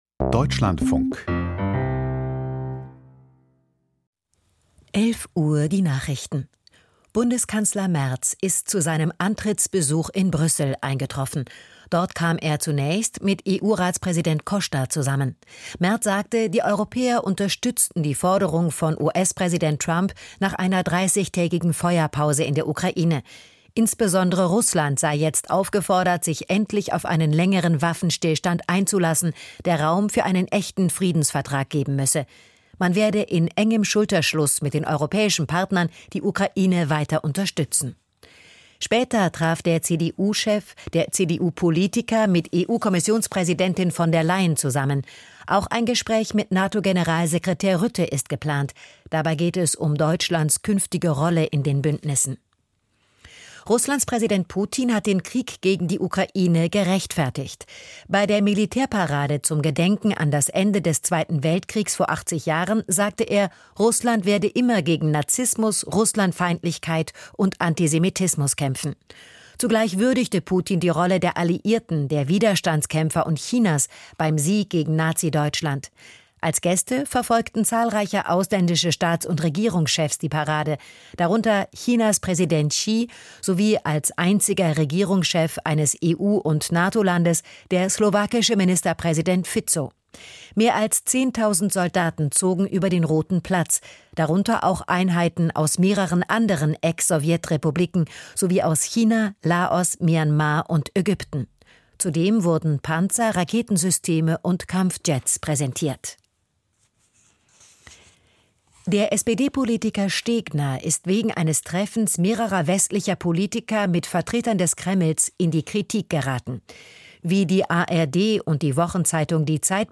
Die Nachrichten vom 09.05.2025, 11:00 Uhr